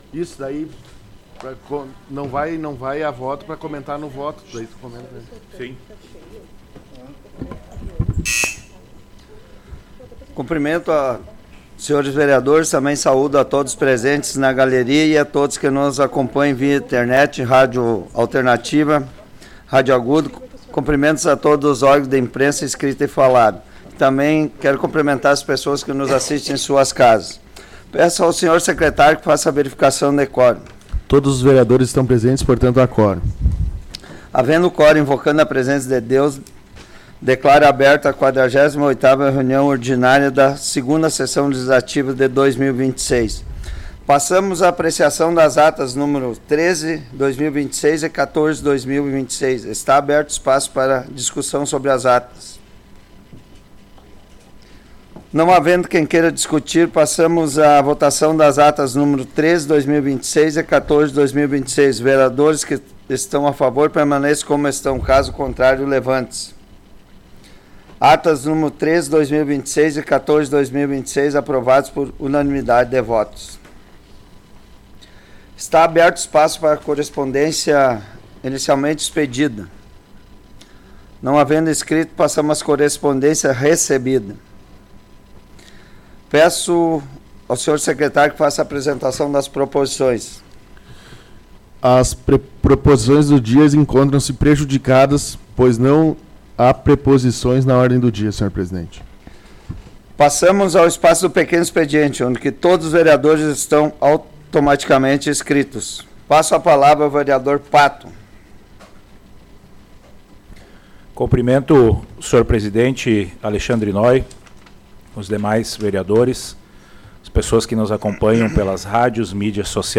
Áudio da 48ª Sessão Plenária Ordinária da 17ª Legislatura, de 23 de março de 2026